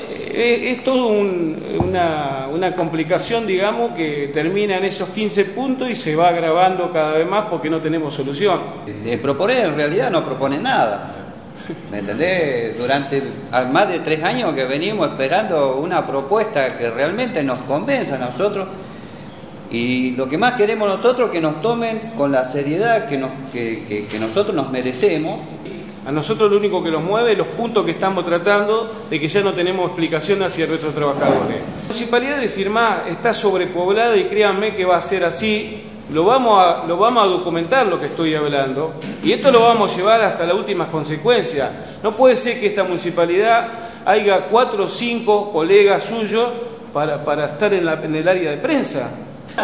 En conferencia de prensa, el Sindicato de Empleados Municipales de Firmat (SITRAMF) anunció el inicio de una medida de fuerza que comenzará este viernes con un paro de 2 horas por turno.